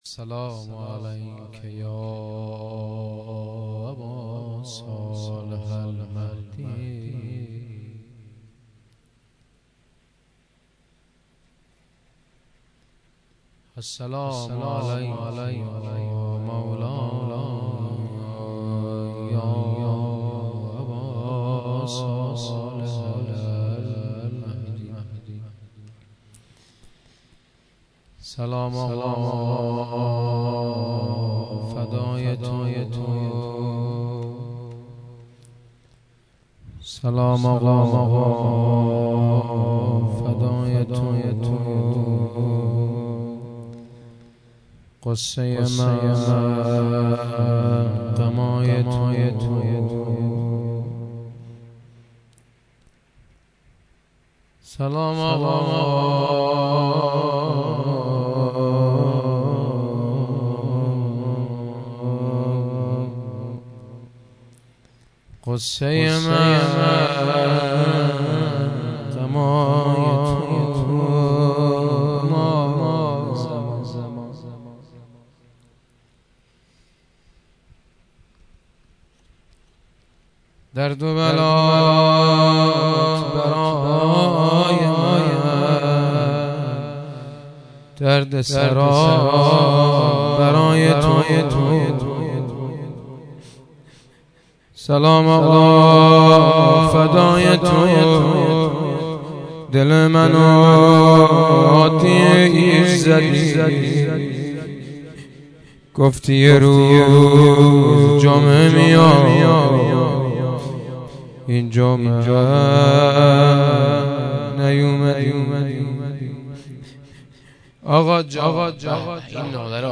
shahadat-emam-javad-93-rozeh-2.mp3